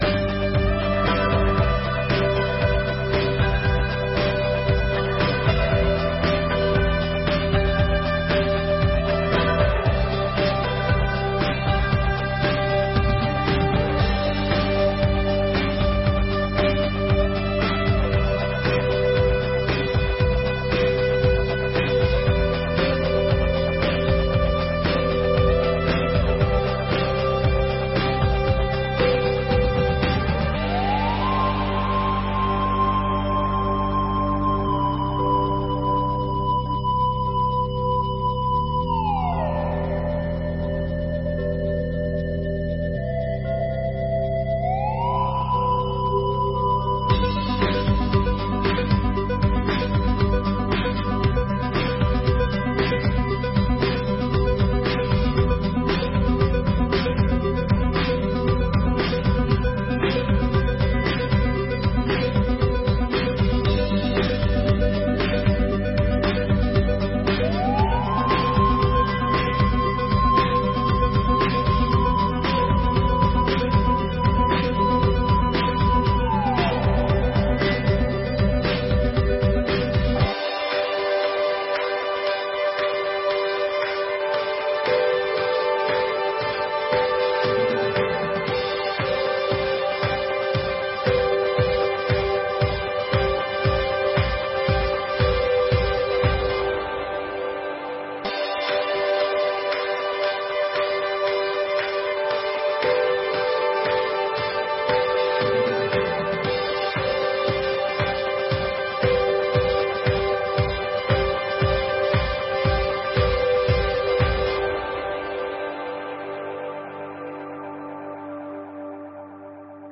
26ª Sessão Ordinária de 2022